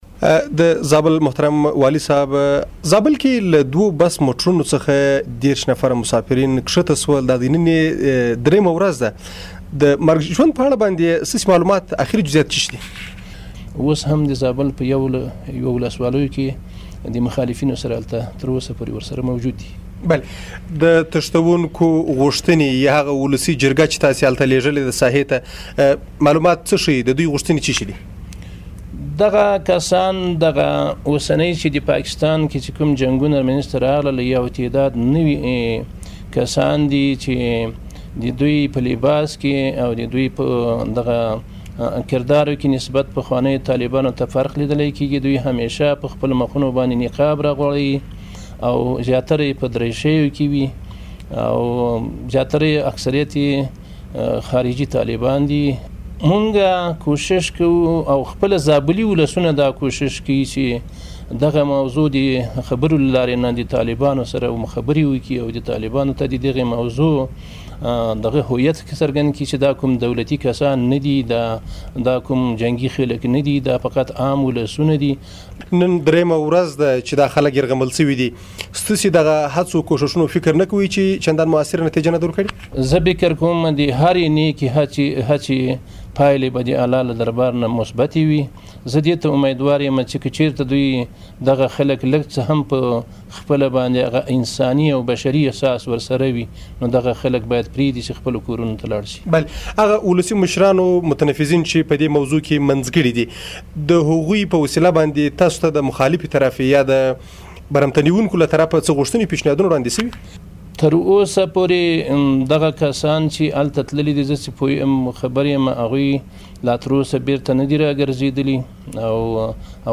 د آشنا رادیو سره د زابل د والي مرکه